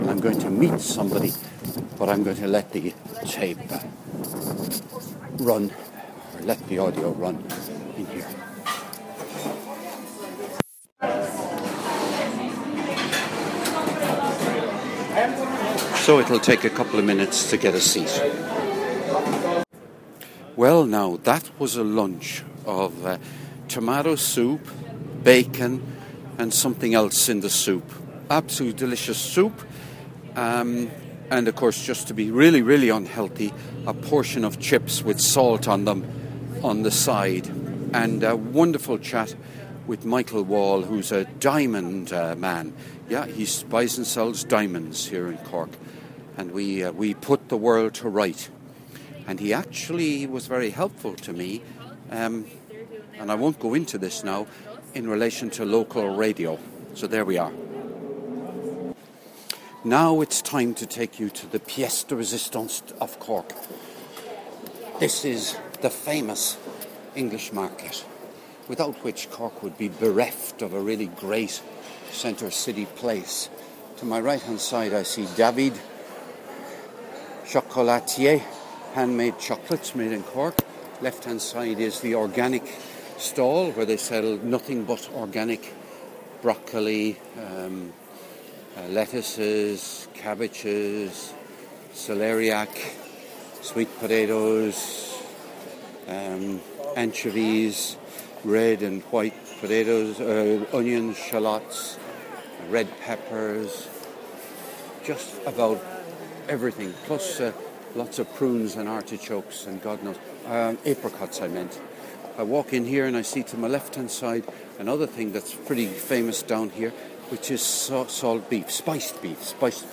Saturday In Cork - Walking Thru Some Favourite Places (Part 2)